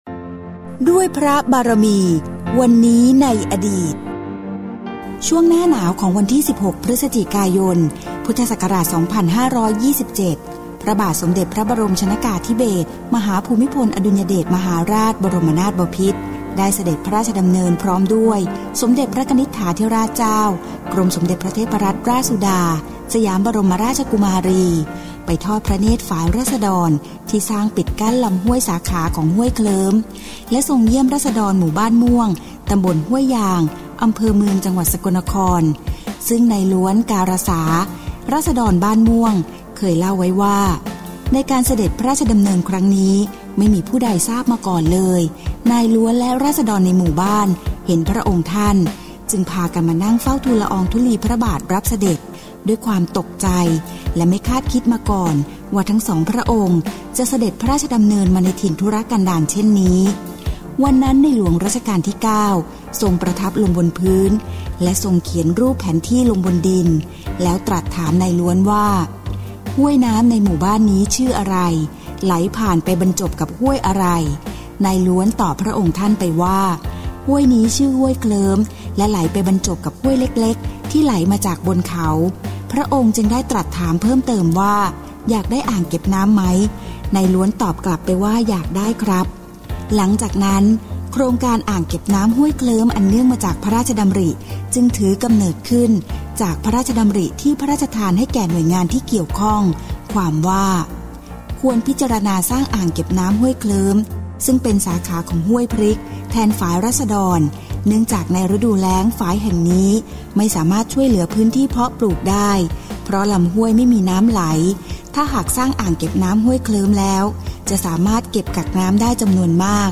สารคดี